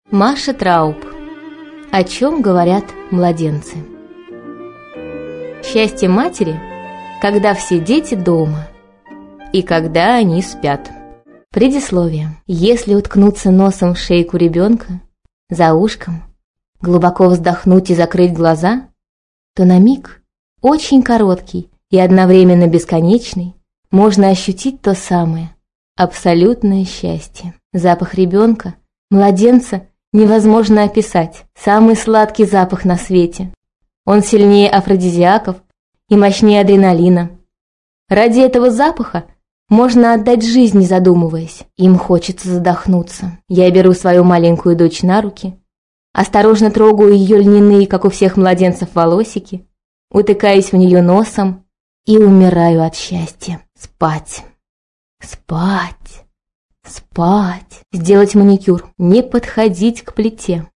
Аудиокнига О чем говорят младенцы | Библиотека аудиокниг
Прослушать и бесплатно скачать фрагмент аудиокниги